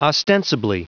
Prononciation du mot ostensibly en anglais (fichier audio)